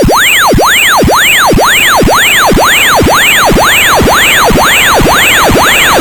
Во время написания программы для микроконтроллера было создано много смешных звуков, например эти: